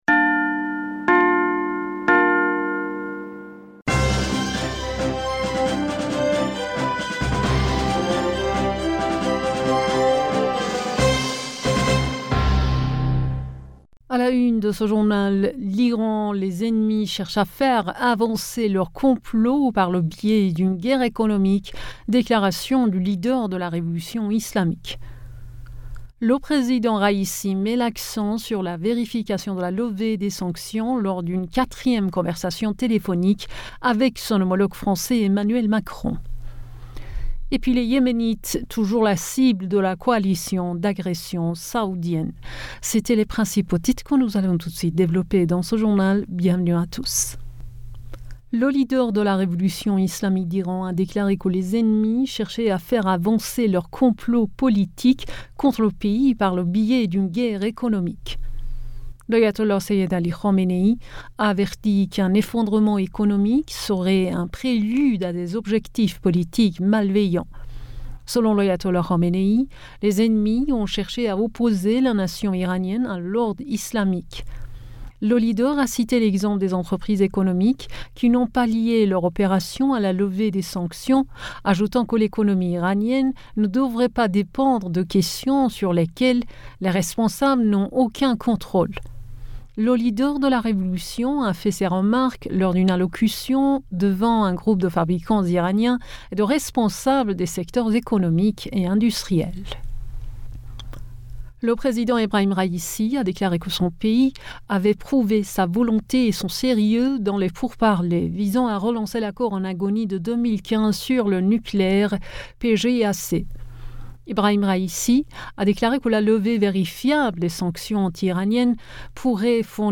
Bulletin d'information Du 30 Janvier 2022